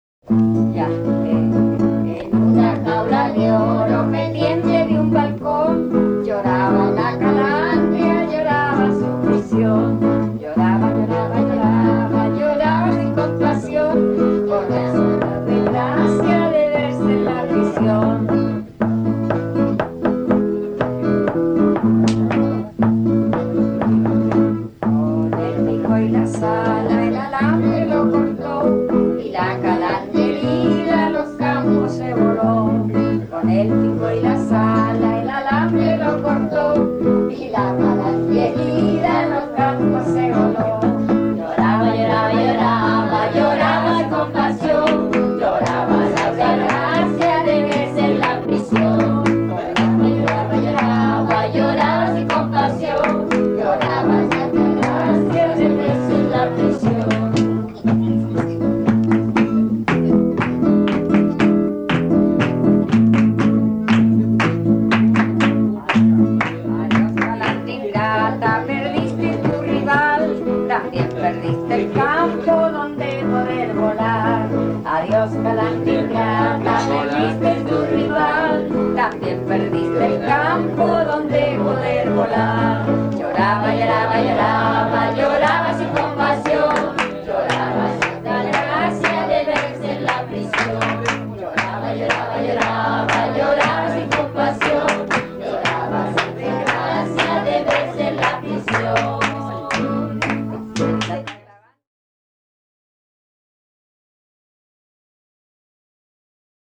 Romance en forma de polca que trata el tema tradicional de "La calandria".
Música tradicional
Folklore
Polca